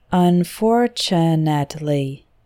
Medium: